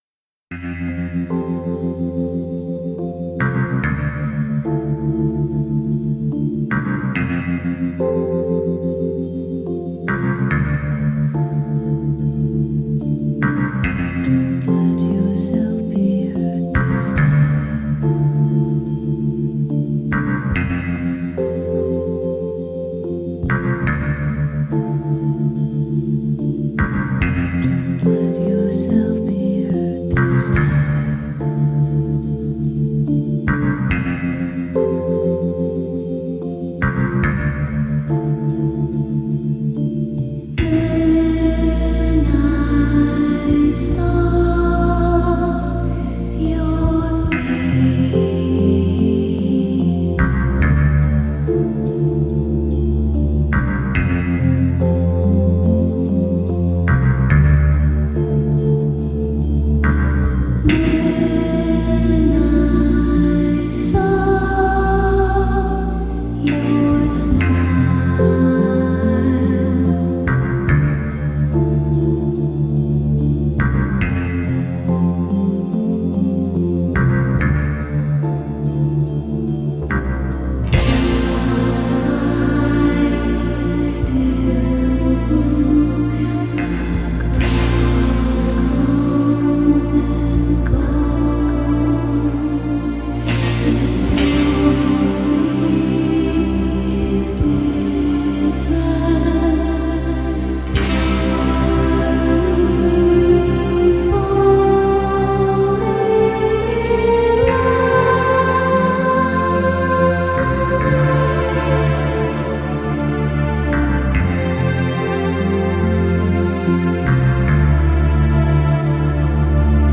Ce générique musical